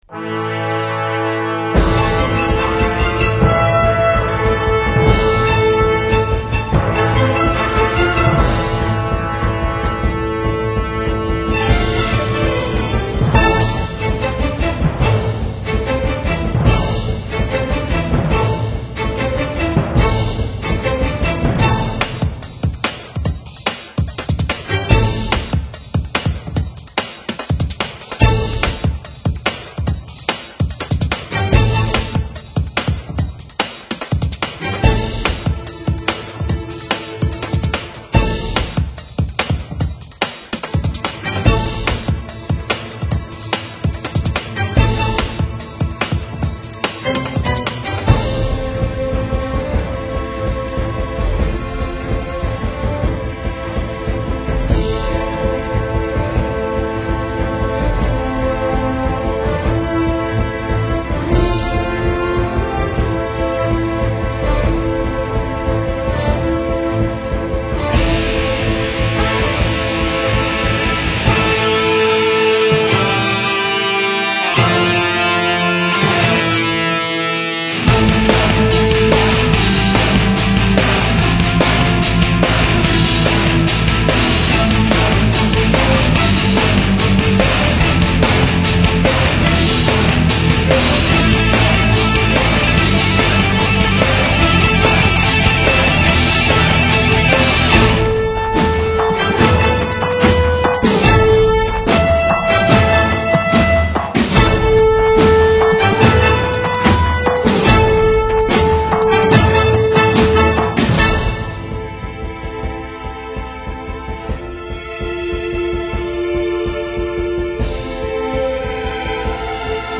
bande son.